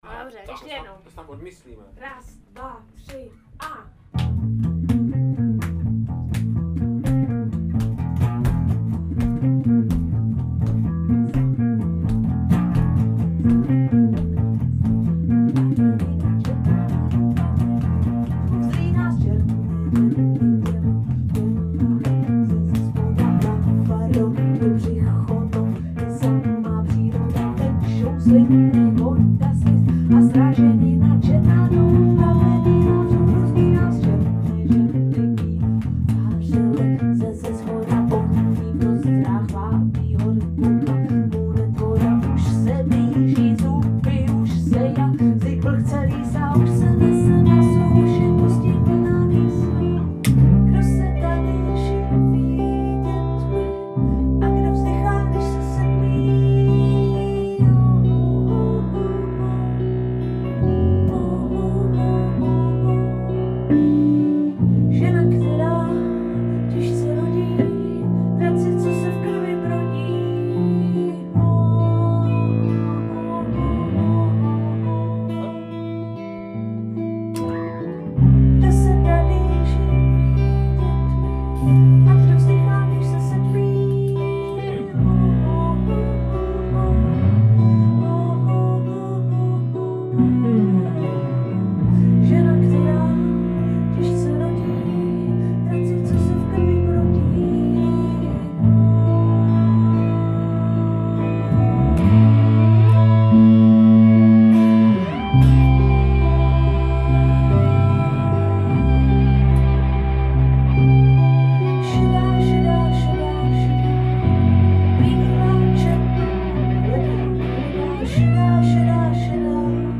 annakonda_cely_basa_kytar_unisono_riff.mp3